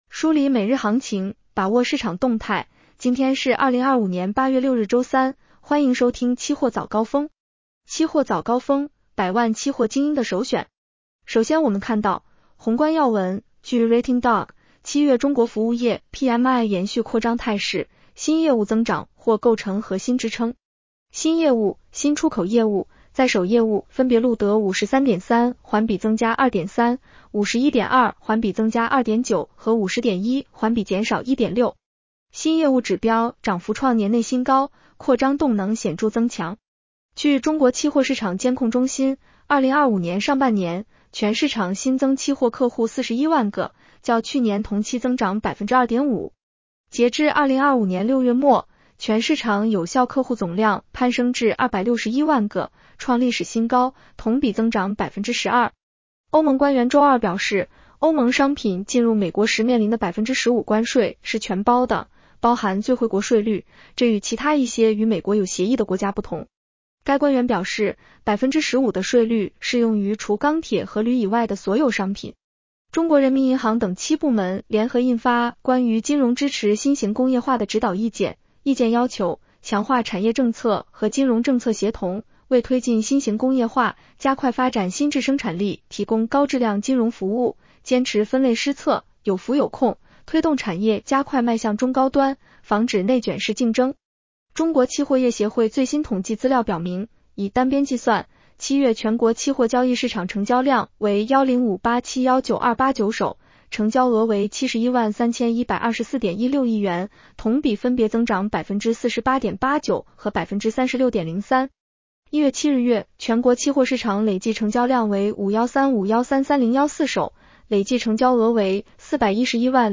期货早高峰-音频版
期货早高峰-音频版 女声普通话版 下载mp3 宏观要闻 1.